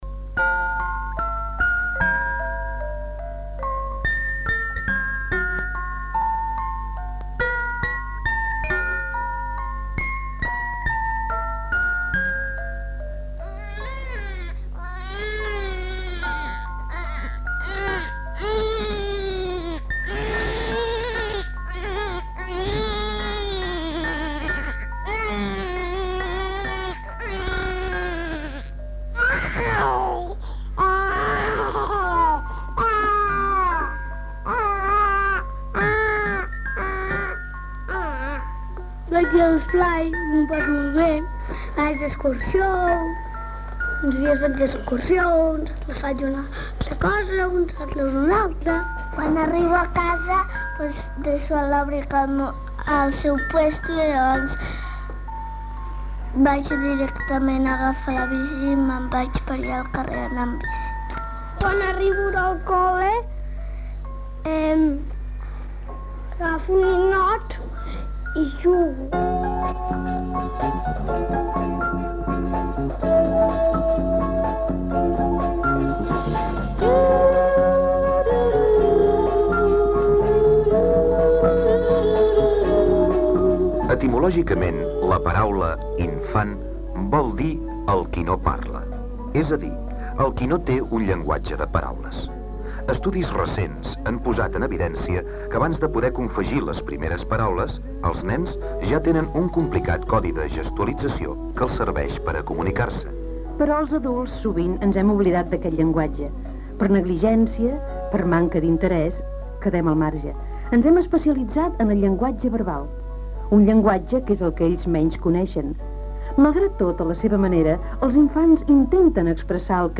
Espai dedicat als infants, amb diversos testimonis